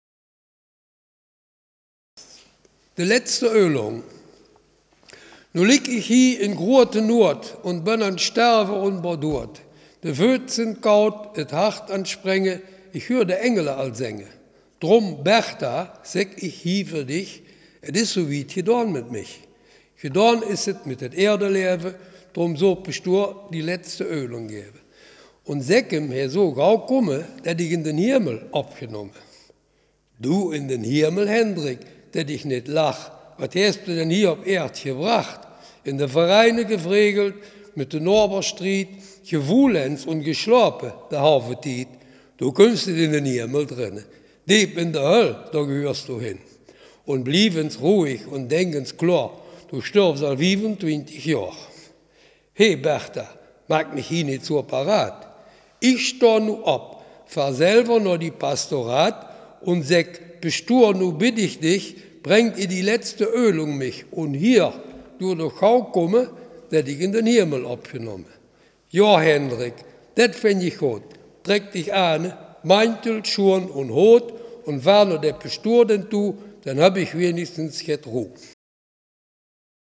Selfkant-Platt
Gedicht